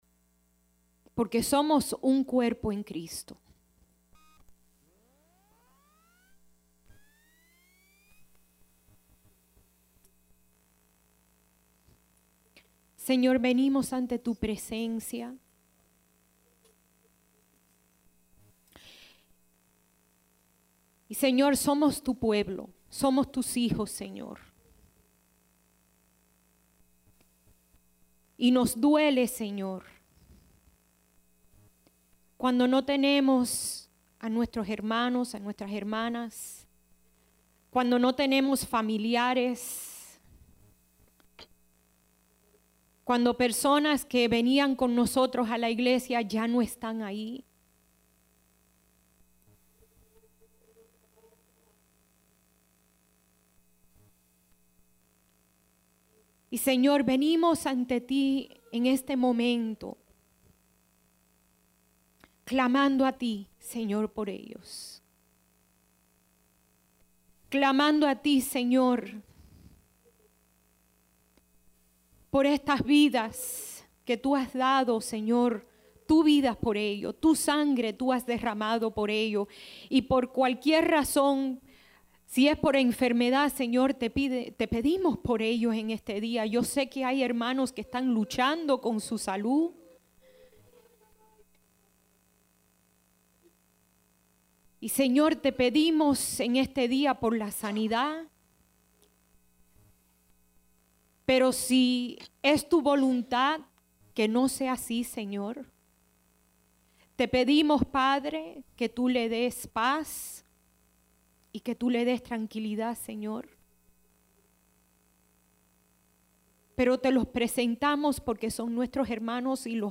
Sermons | Iglesia Centro Evangelico